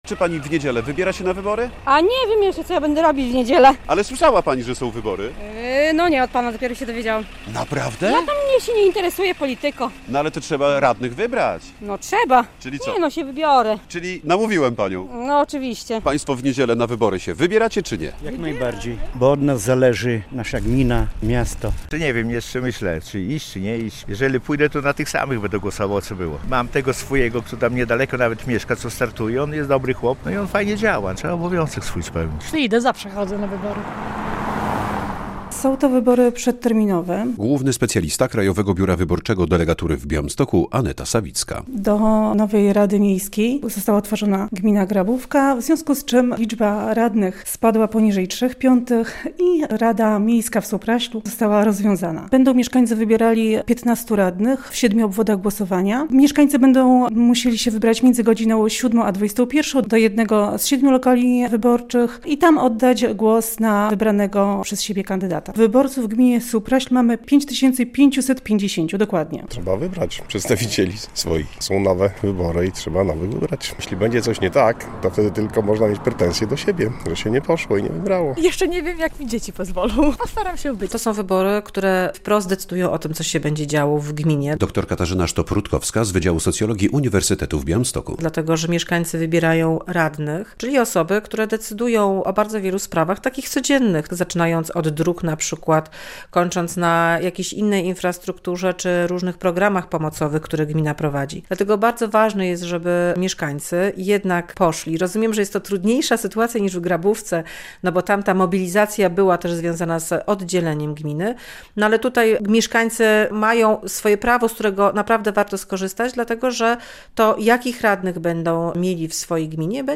Radio Białystok | Wiadomości | Wiadomości - 30 marca mieszkańcy gminy Supraśl wybiorą nowych radnych